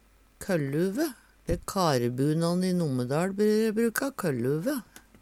kølluve - Numedalsmål (en-US)